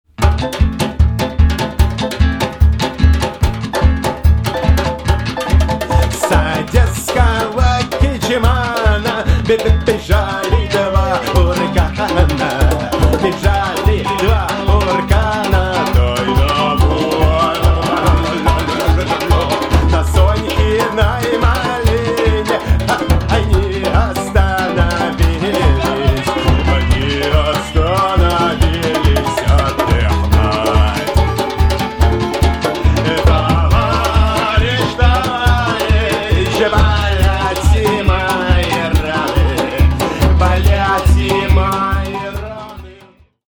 Make a drum set out of Djembe, Darabuka and Talking Drums.